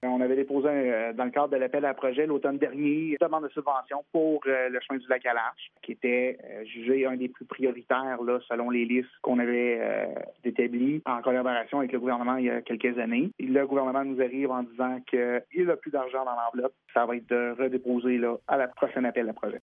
Cayamant a demandé 2,4 millions de dollars pour la réfection complète de 14 km du chemin du Lac-à-Larche. Nicolas Malette, maire de Cayamant, affirme que ces travaux ont été jugés prioritaires :